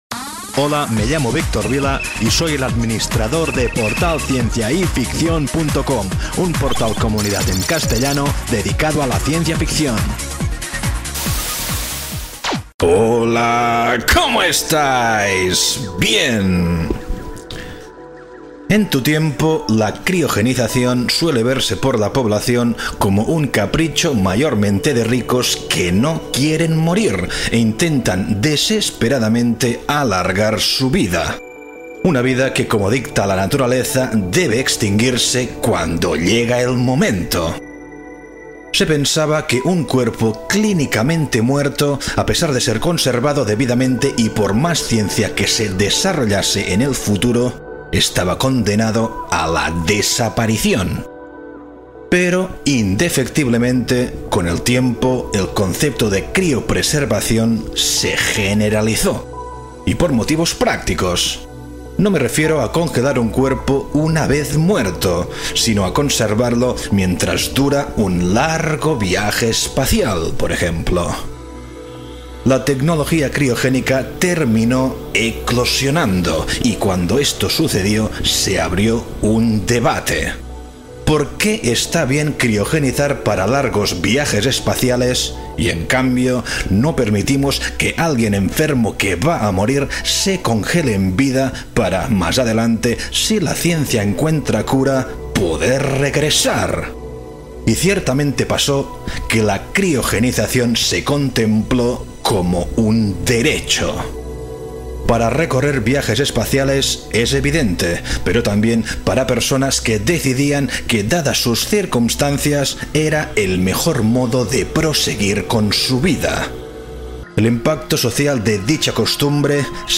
En este audiorelato narro un poco las causas de semejante posible (o probable, quién sabe), metamorfosis...
Audiorelato DIARIO DE UN POSTBIOLOGICO - Portalcienciayficcion.mp3